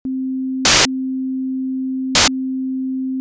Вот первый эксперимент: синусоида, установленная с помощью фейдеров Live на -10 dBFS, прерываемая всплесками белого шума, сначала 200 мс, а затем 125 мс в длину.
Я могу различить, что синусоида прерывается, и иллюзии непрерывности не возникает.